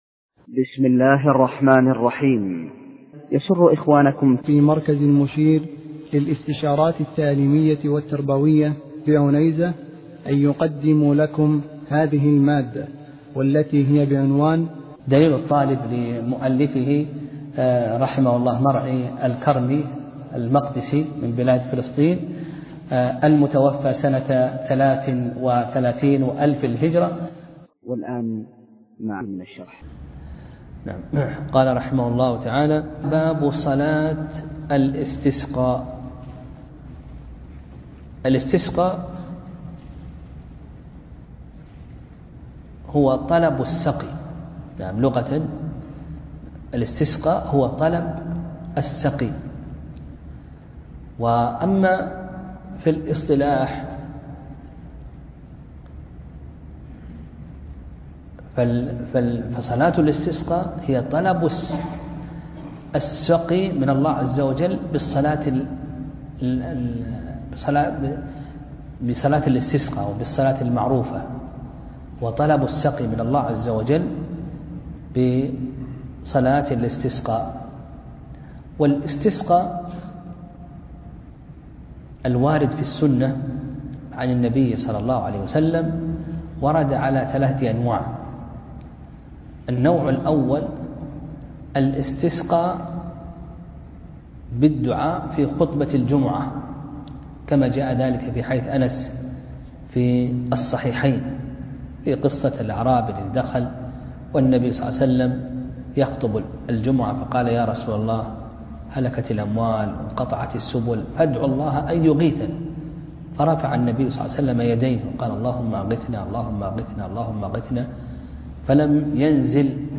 درس (24) : باب صلاة الاستسقاء